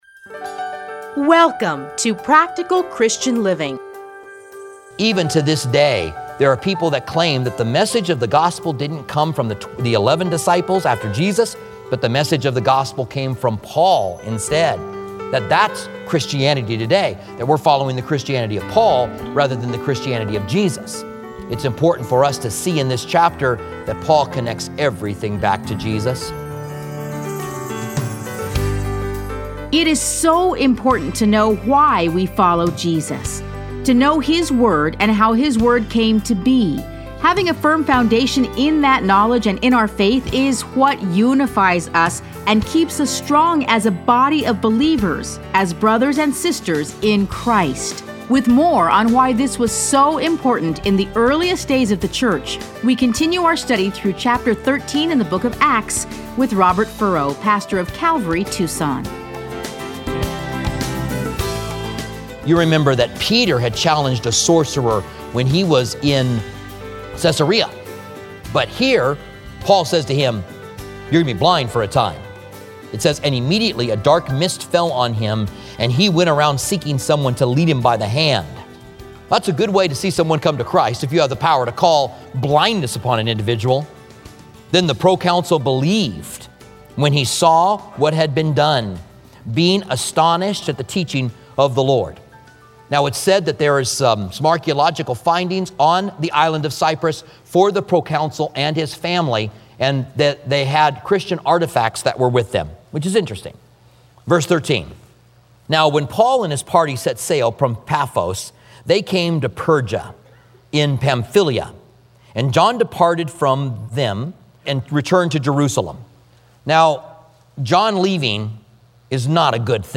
Listen to a teaching from Acts 13.